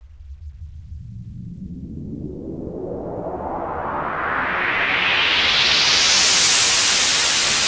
cch_fx_loop_metallic_125.wav